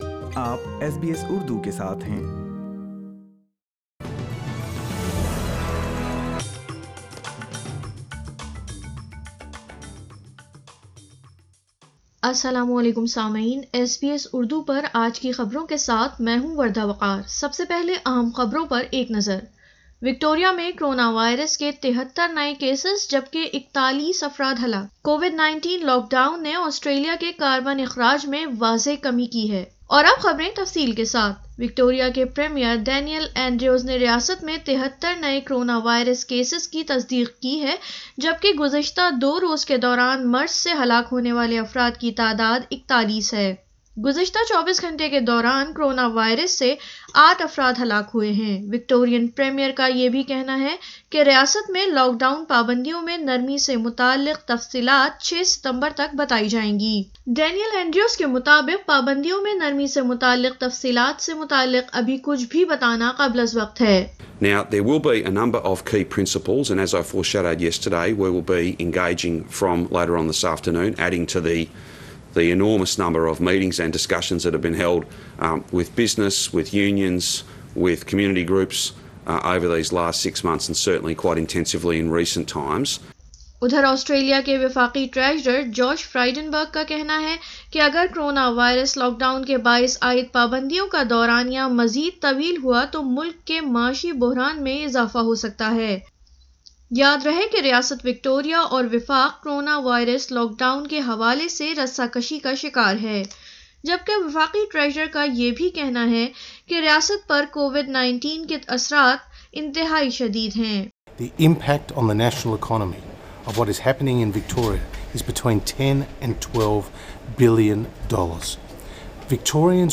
اردو خبریں 31 اگست 2020